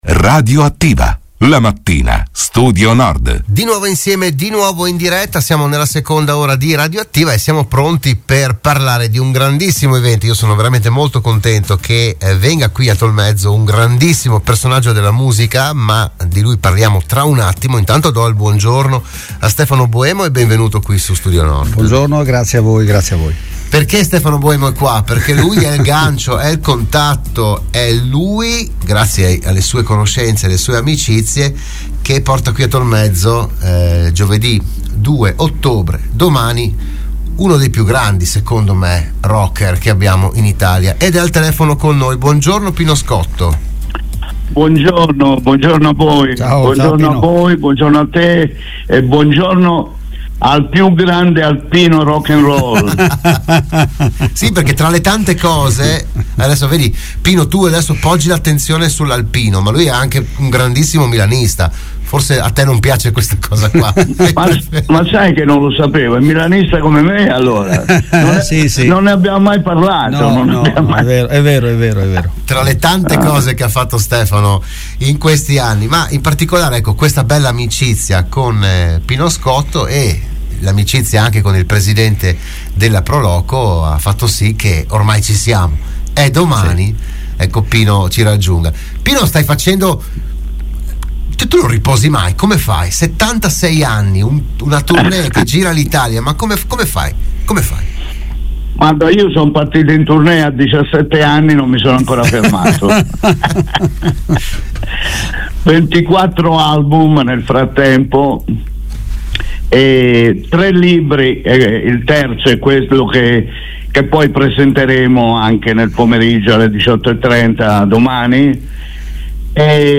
PODCAST – Pino Scotto arriva a Tolmezzo, l’intervista a Radio Studio Nord